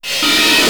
vf_shake.wav